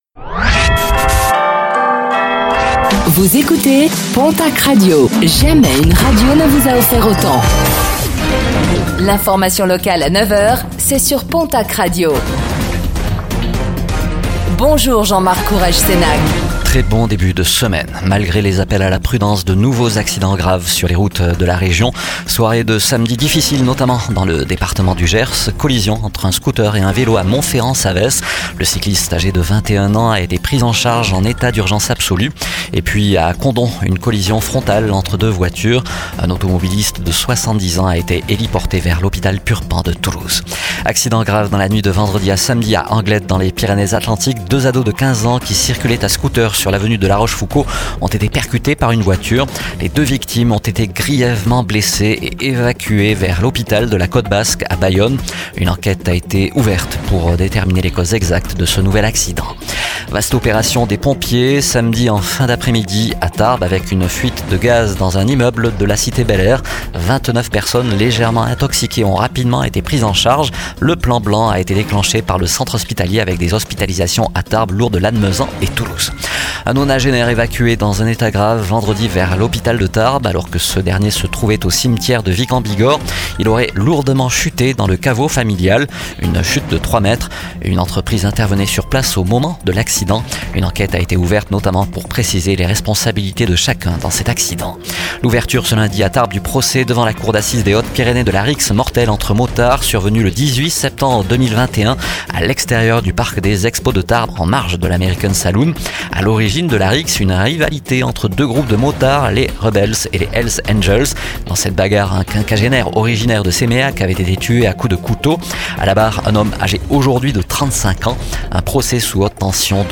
Réécoutez le flash d'information locale de ce lundi 23 juin 2025